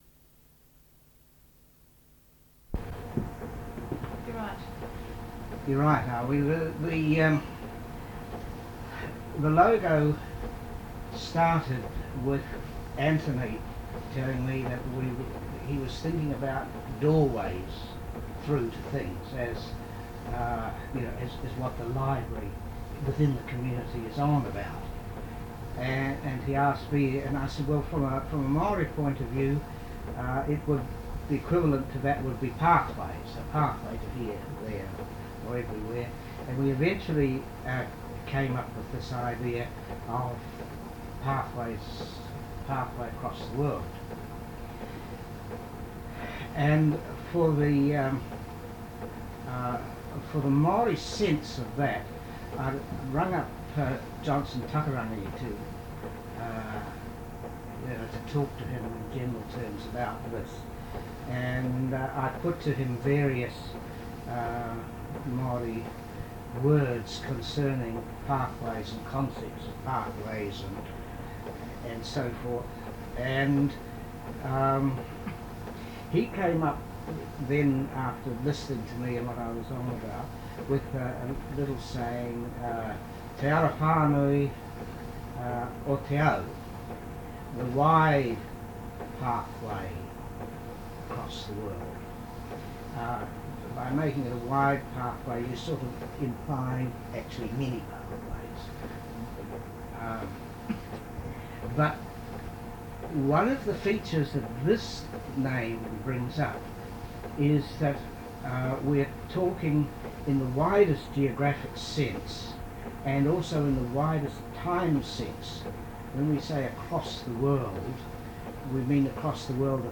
Oral Interview - John Bevan Ford - Manawatū Heritage